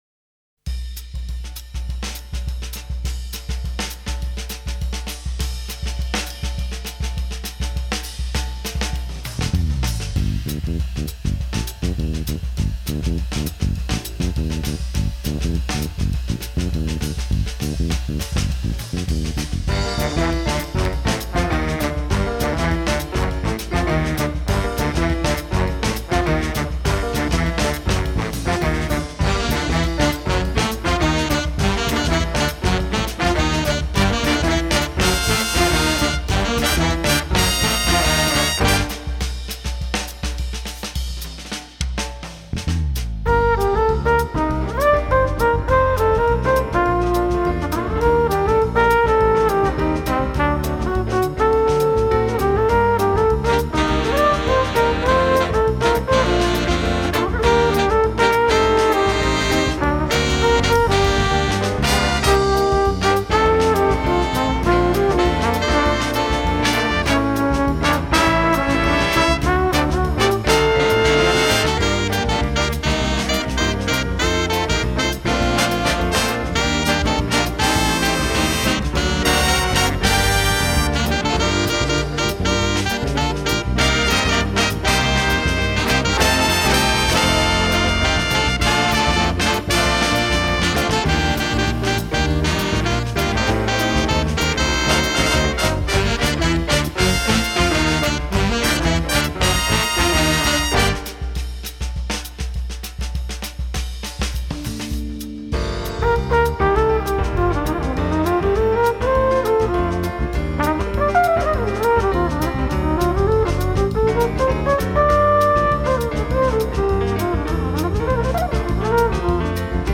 MUSIC FOR BIG BAND
Category: Big Band